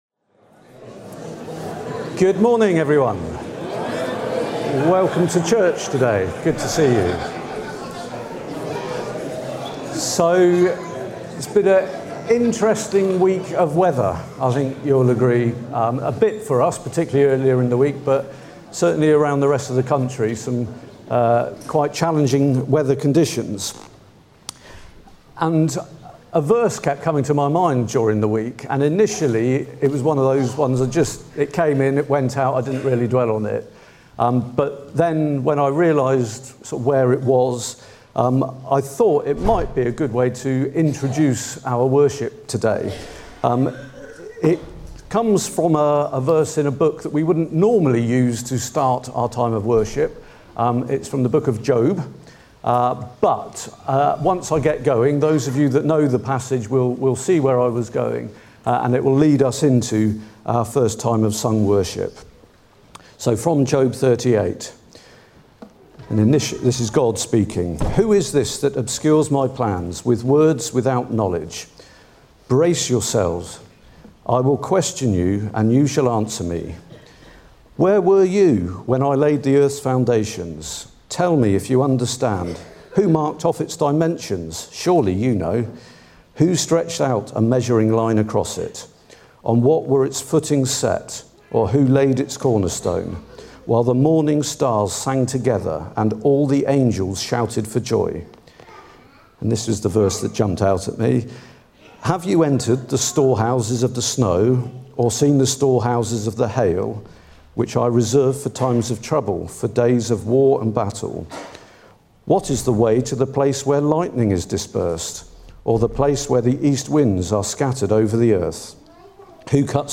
11 January 2026 – Morning Service
Service Type: Morning Service